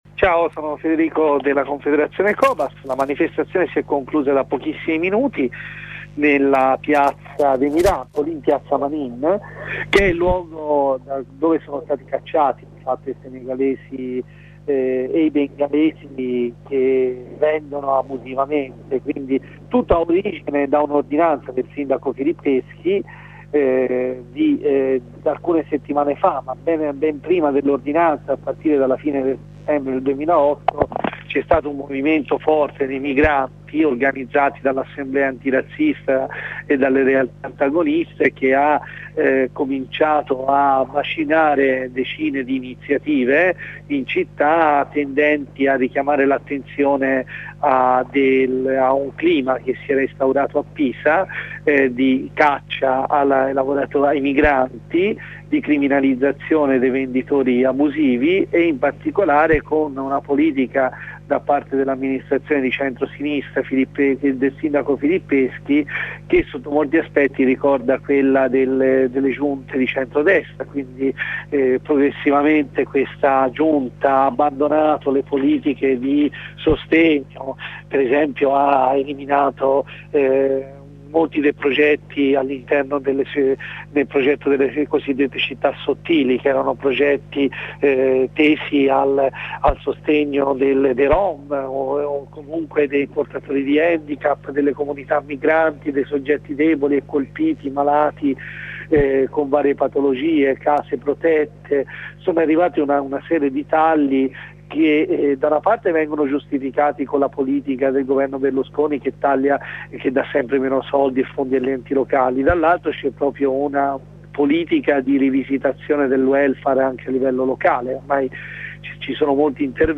Corrispondenza con un compagno dei COBAS.